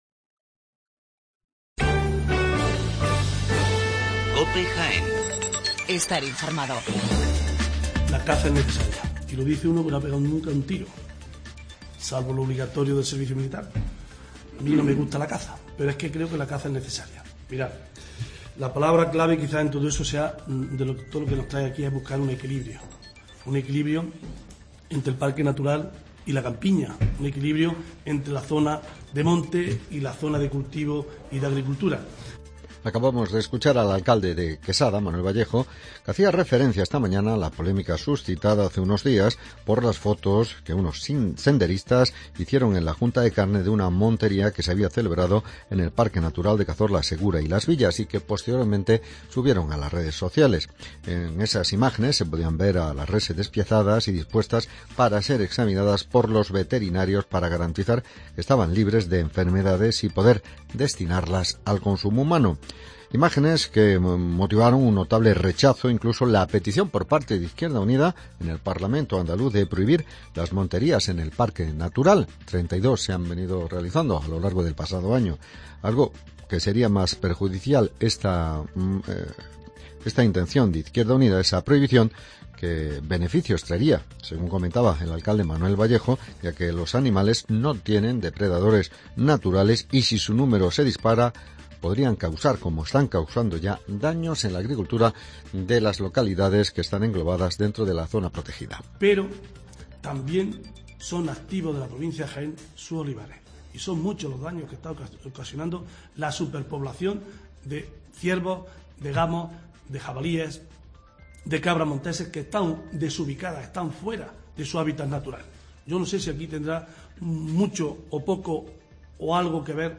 Las noticias del 1 de marzo de 2017